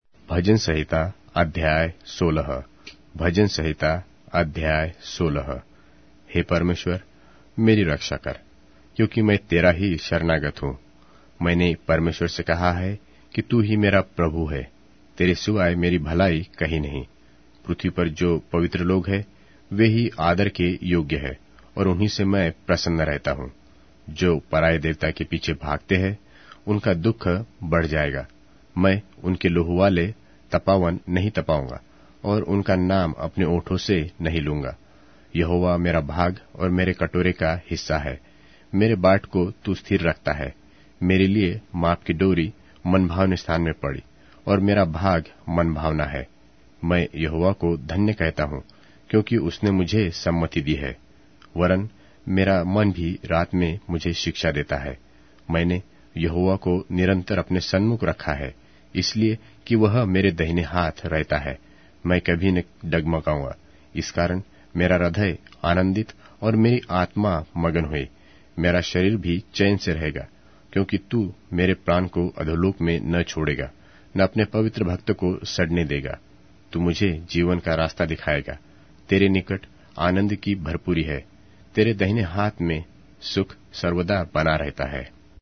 Hindi Audio Bible - Psalms 131 in Irvta bible version